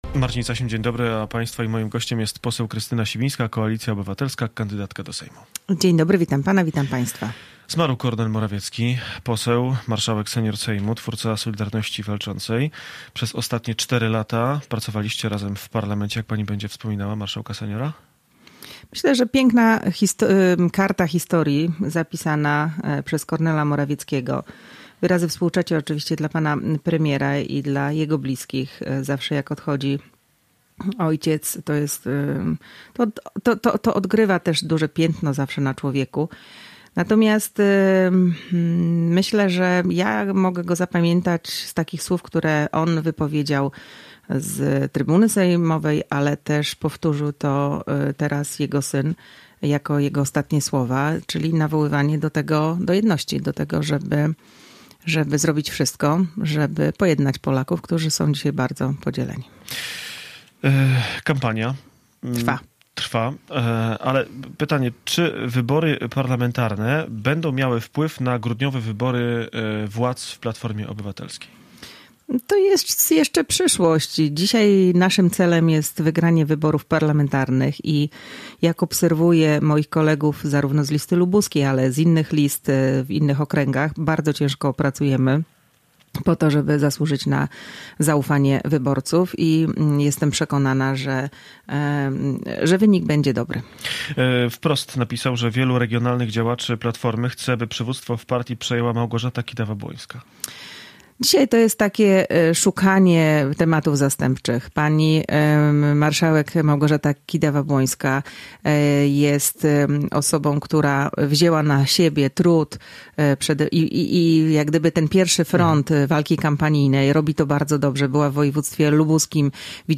Z poseł klubu Platforma Obywatelska – Koalicja Obywatelska i kandydatką do Sejmu RP rozmawiał